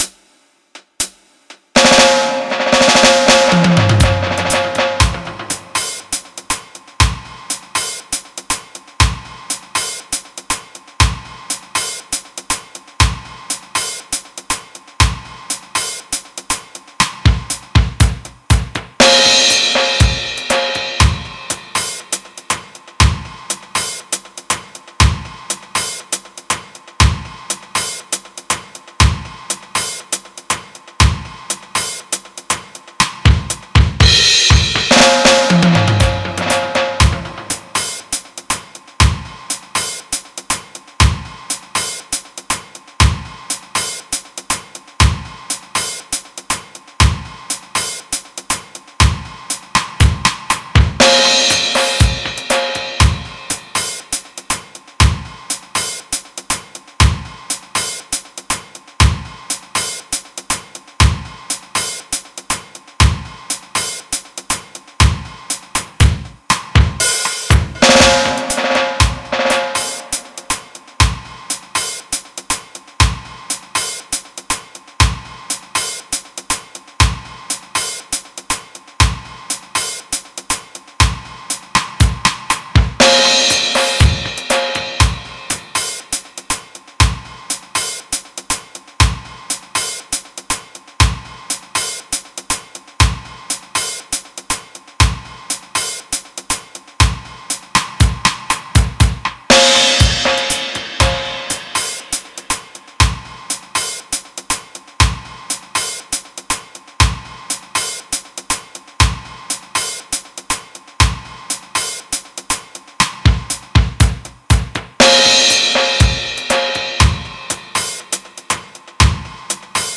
reggae1-dub-60bpm.mp3